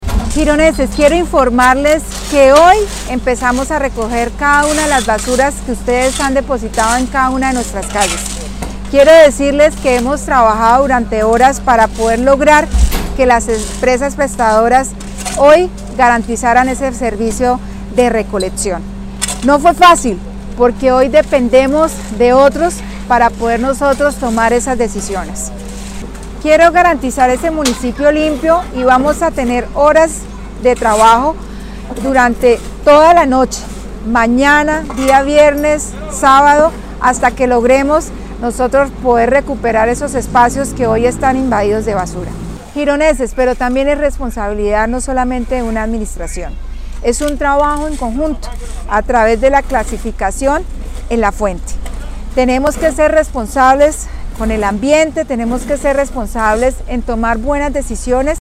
Yulia Rodríguez, Alcaldesa de Girón.mp3